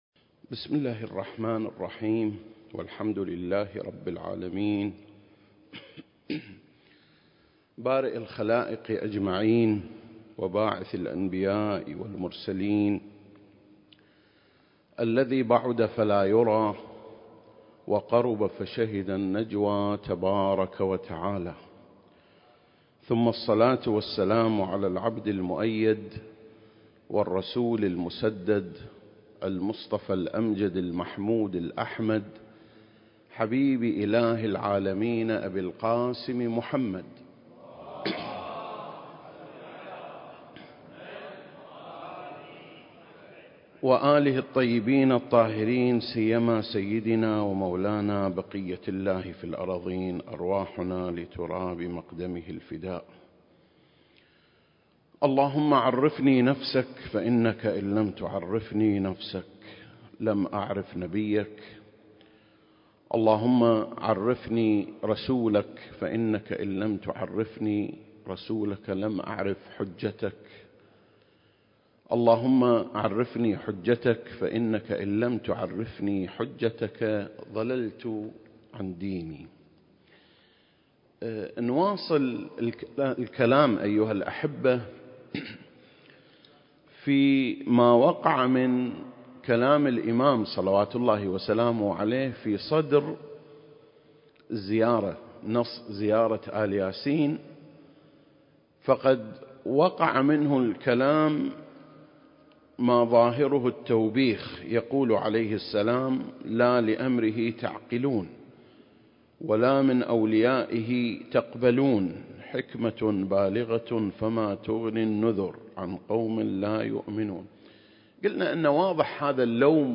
سلسلة: شرح زيارة آل ياسين (15) - قصة التوبيخ (3) المكان: مسجد مقامس - الكويت التاريخ: 2021